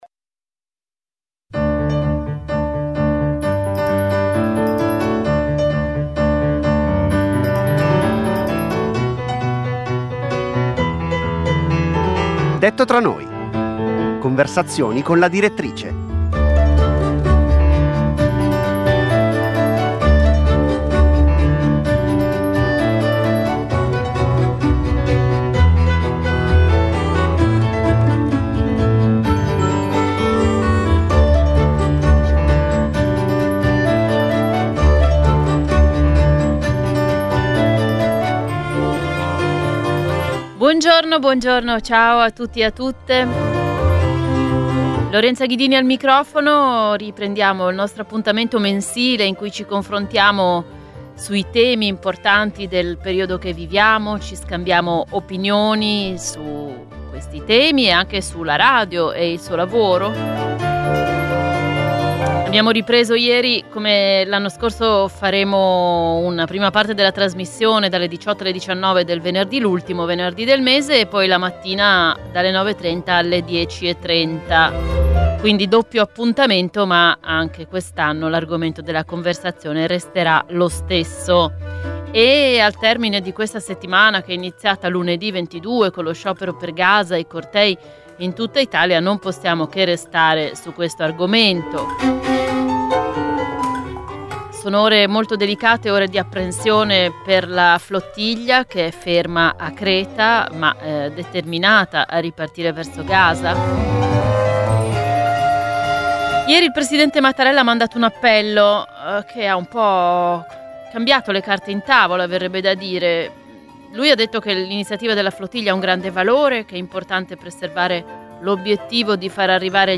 Conversazioni con la direttrice.